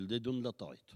ambiance et archive
Catégorie Locution